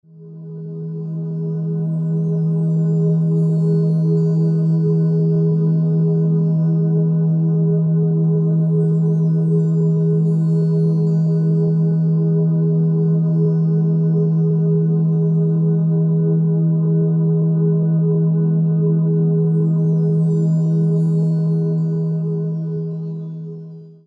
Brown noise (a sound that helps you fall asleep)
• Quality: High